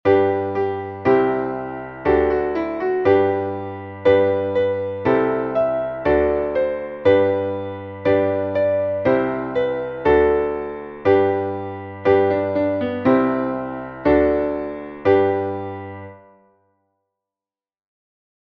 Traditioneller Kanon